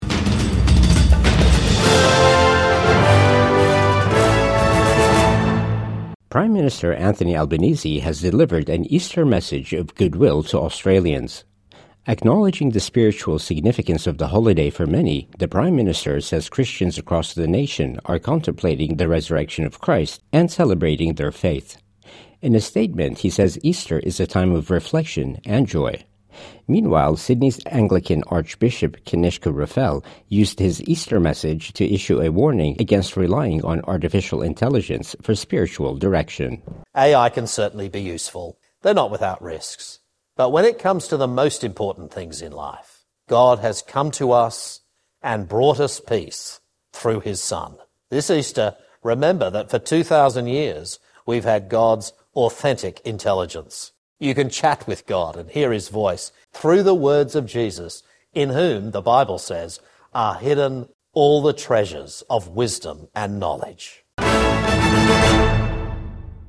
Prime Minister Anthony Albanese delivers Easter message of goodwill